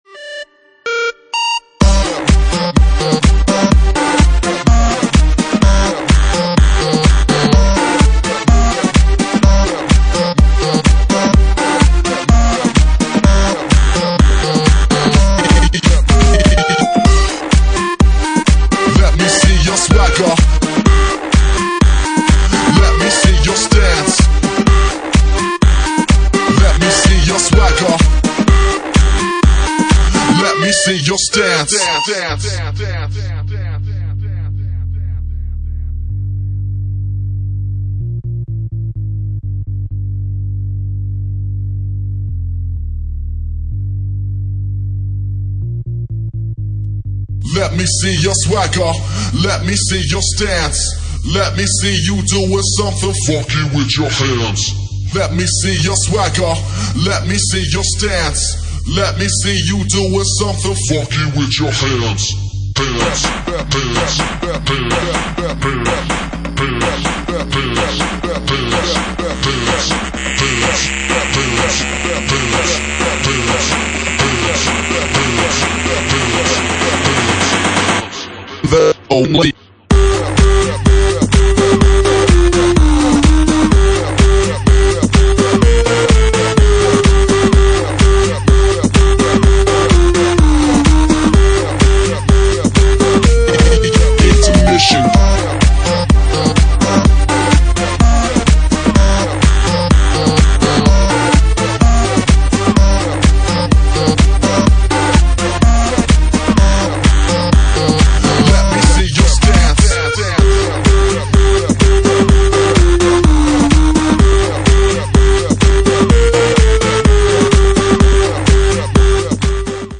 Genre:Jacking House
Jacking House at 126 bpm